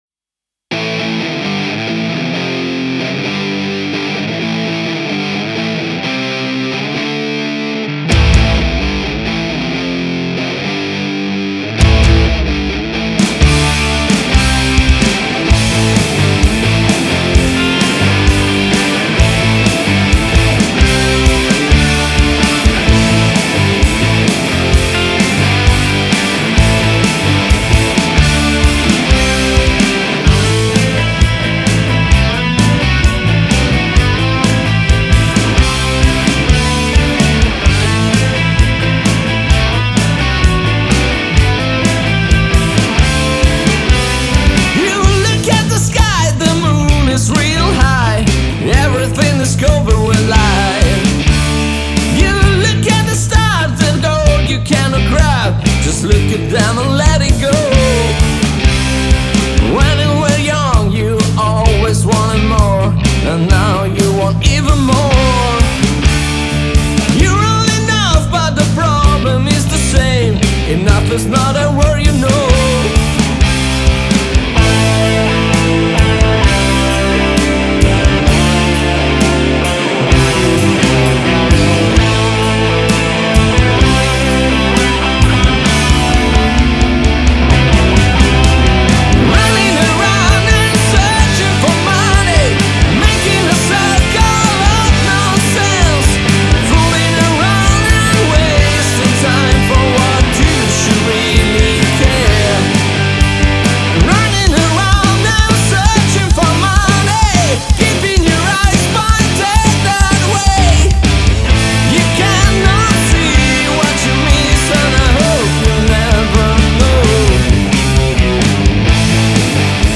Studio di registrazione e sale prova a Capriolo, Brescia.